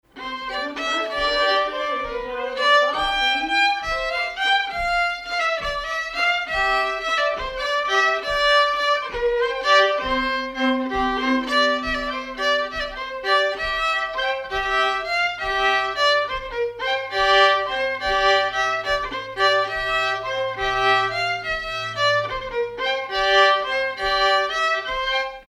circonstance : bal, dancerie
Pièce musicale inédite